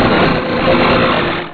Cri de Tropius dans Pokémon Rubis et Saphir.